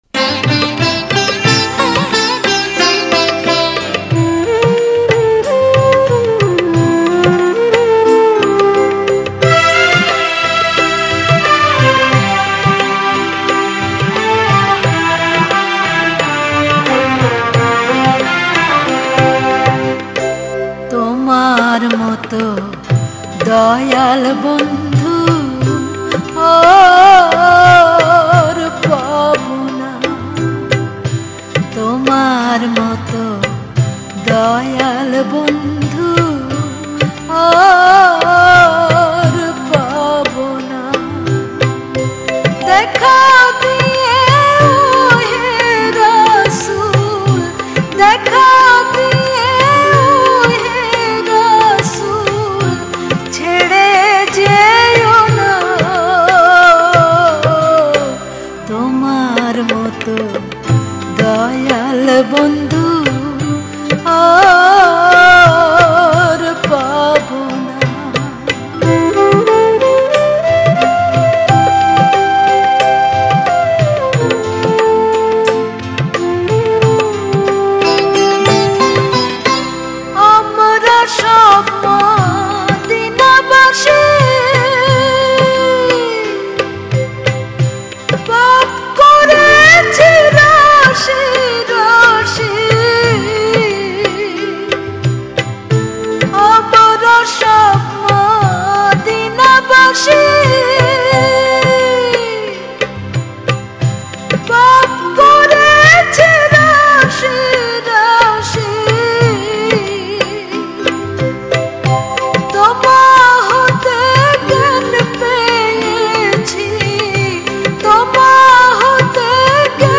Bengali Kalam